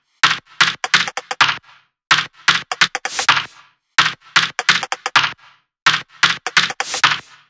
VFH1 128BPM Southern Kit